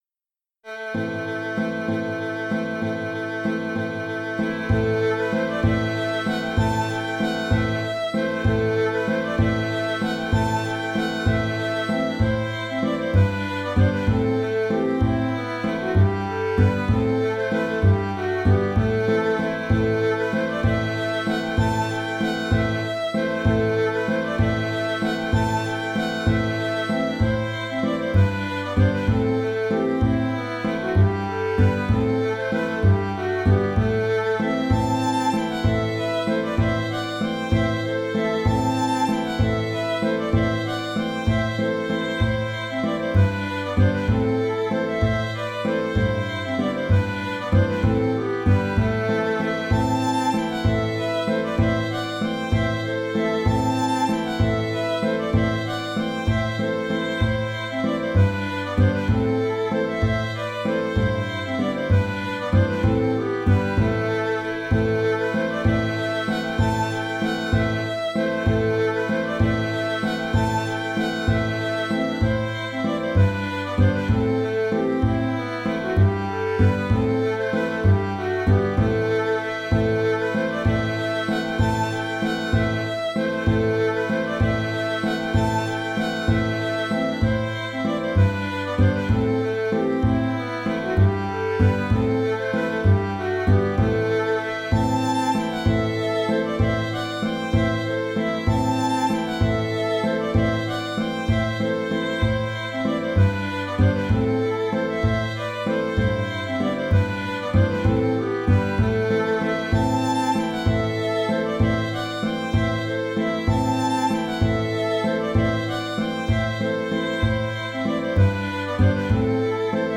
Bourrée 3 temps
J'ai appelé cette bourrée "dorienne" parce que je l'ai composée en utilisant le mode dorien.
Ainsi, dans la gamme utilisée dans ce morceau, c'est la gamme de La m (Am) avec un fa #.
Elle a la couleur de la musique médiévale.